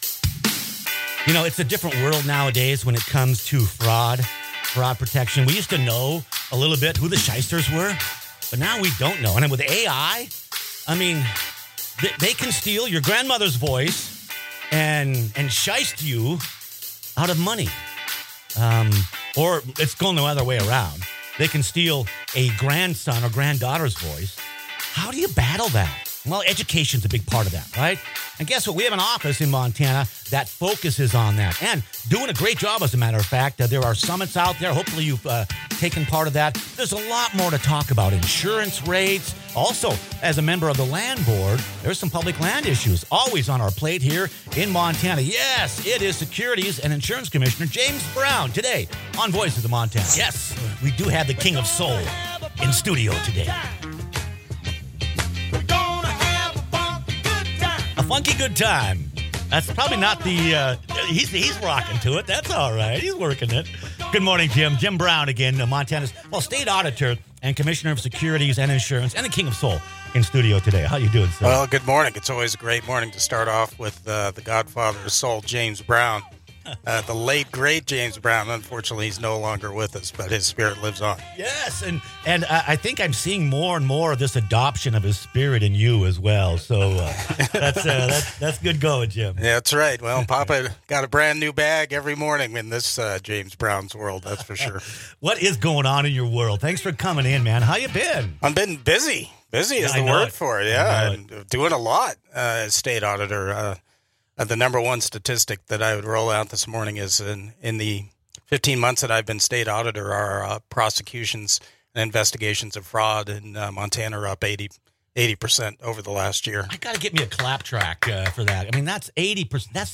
Fraud is evolving fast in Montana, and AI is making scams more convincing than ever. Montana Commissioner of Securities and Insurance Jim Brown joins Voices of Montana to break down the surge in crypto fraud, deepfake scams, and what you can do to protect yourself.